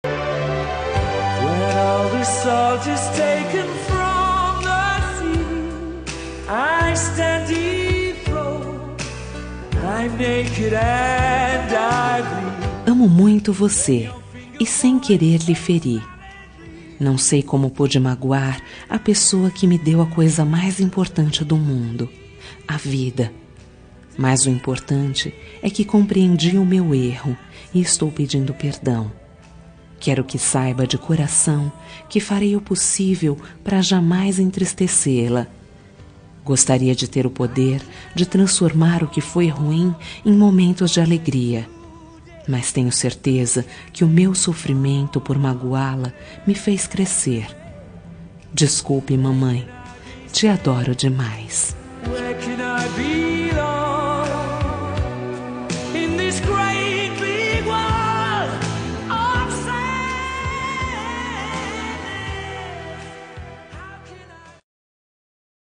Reconciliação Familiar – Voz Feminina – Cód: 088722 – Mãe